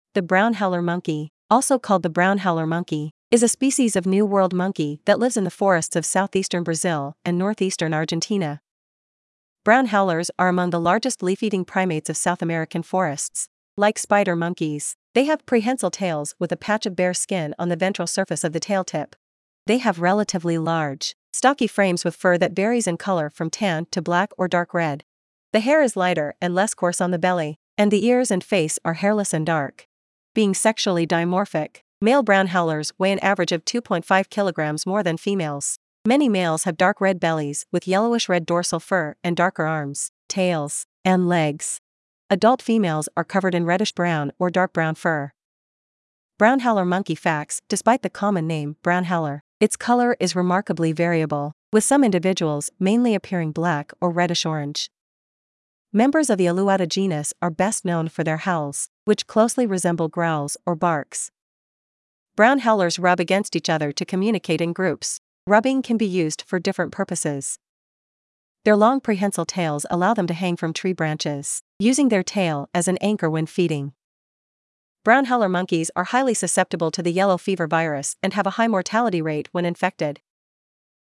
Brown Howler Monkey
• Members of the Alouatta genus are best known for their howls, which closely resemble growls or barks.
Brown-Howler-Monkey.mp3